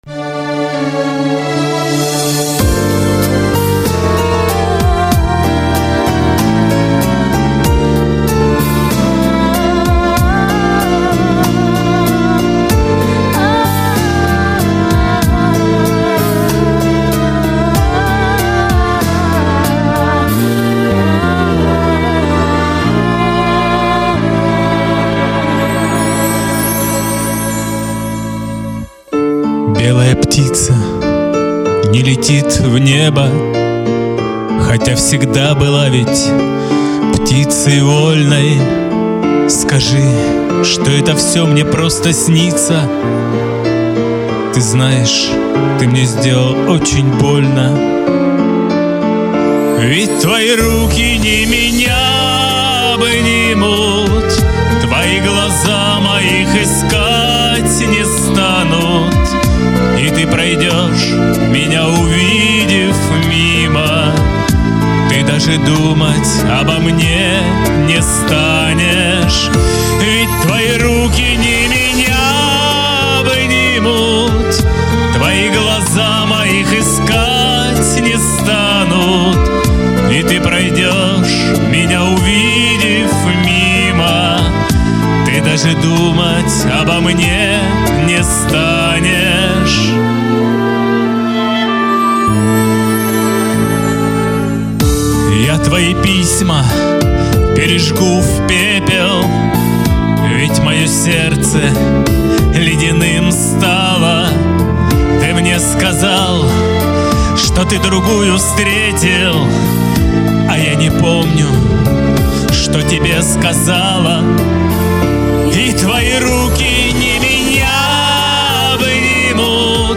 не вписался в тональность.